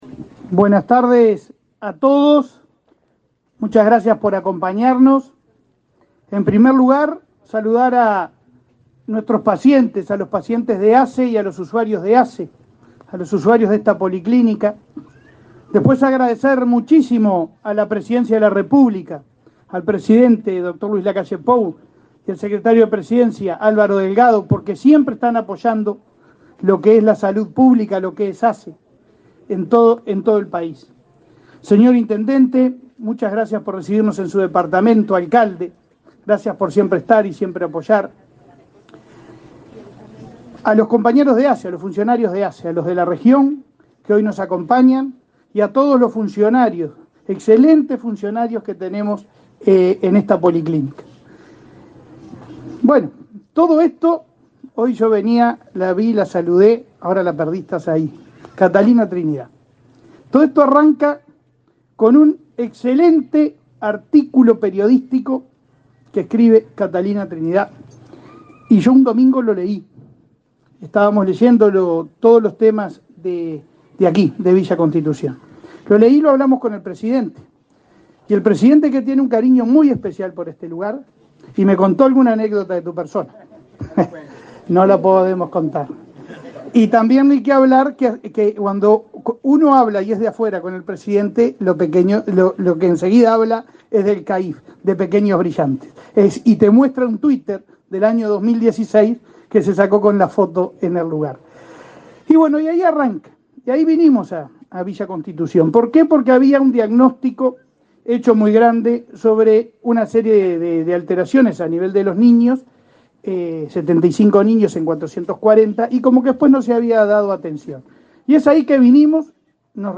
Palabras del presidente de ASSE, Leonardo Cipriani
Palabras del presidente de ASSE, Leonardo Cipriani 13/09/2022 Compartir Facebook X Copiar enlace WhatsApp LinkedIn Con la presencia del presidente de la República, Luis Lacalle Pou, la Administración de los Servicios de Salud del Estado (ASSE) inauguró obras de remodelación y ampliación de la policlínica de Villa Constitución, en Salto, este 13 de setiembre. En el acto, el presiente de ASSE realizó declaraciones.